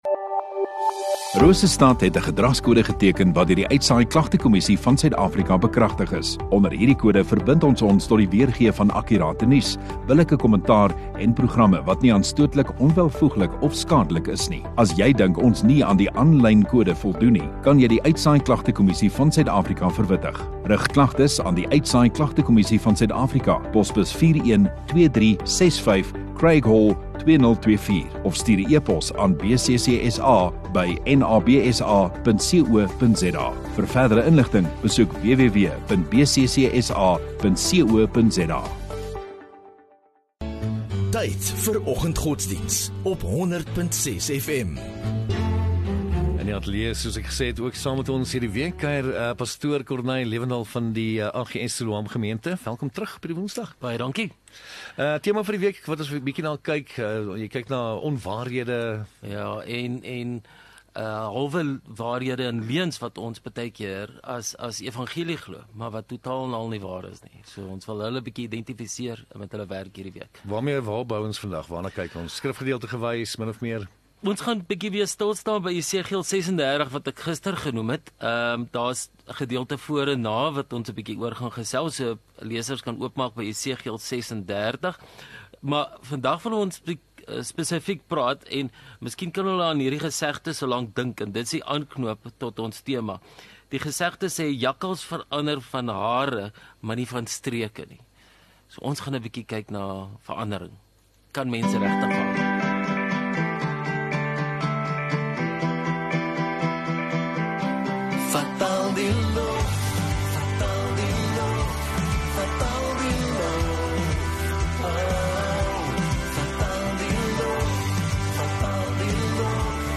10 Apr Woensdag Oggenddiens